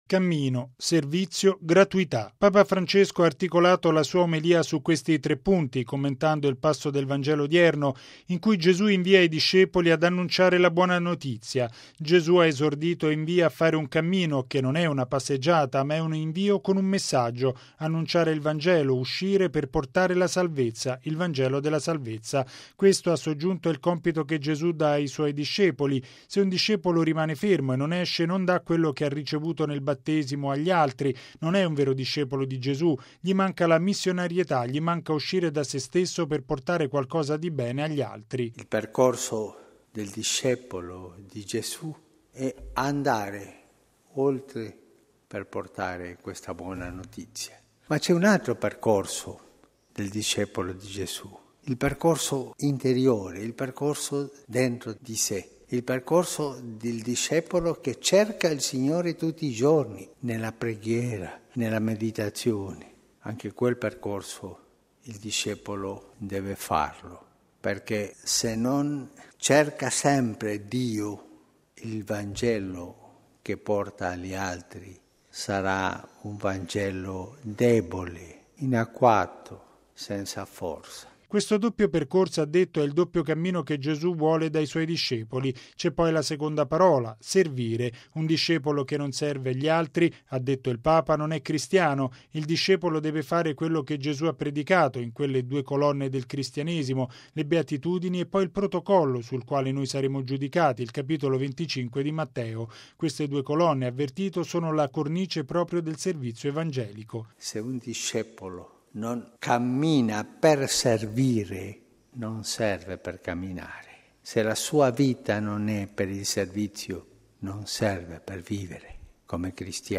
Bollettino Radiogiornale del 11/06/2015